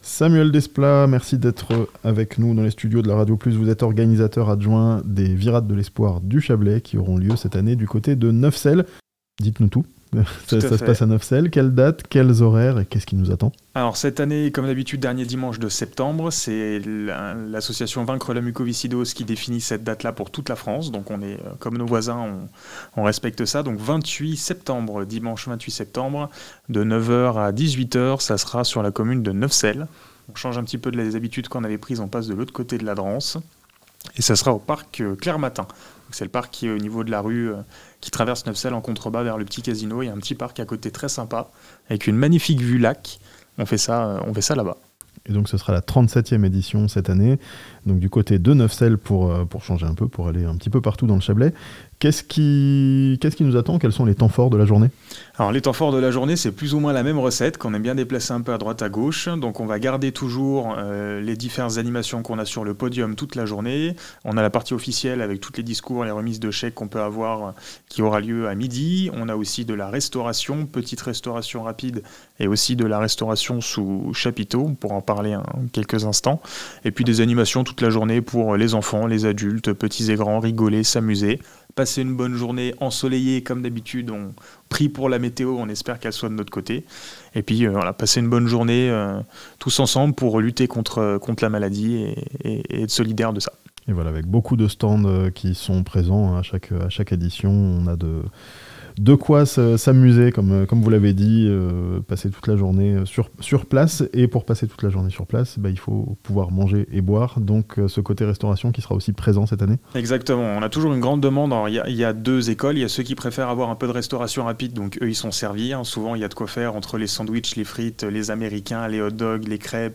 Un documentaire projeté ce vendredi à Neuvecelle, avant les Virades de l'Espoir du Chablais le 28 septembre (interview)